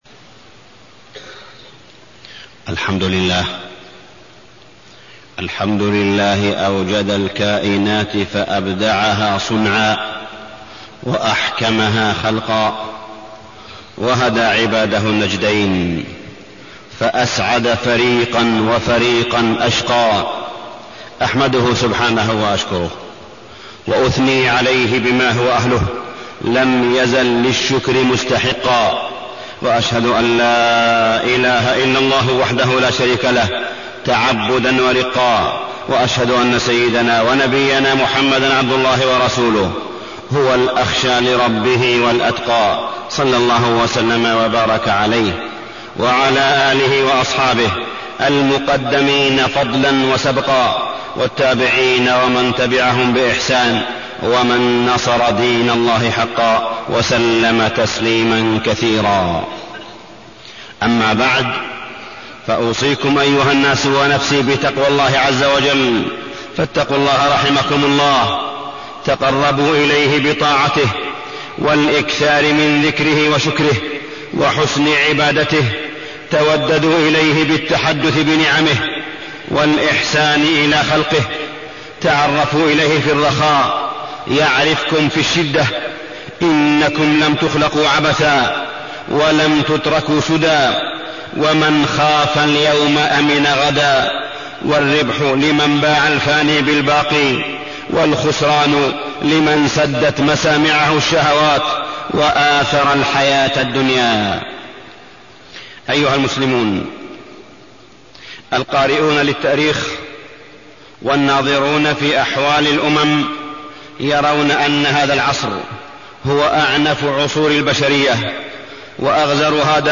تاريخ النشر ٨ رمضان ١٤٢٢ هـ المكان: المسجد الحرام الشيخ: معالي الشيخ أ.د. صالح بن عبدالله بن حميد معالي الشيخ أ.د. صالح بن عبدالله بن حميد فضل الصلاة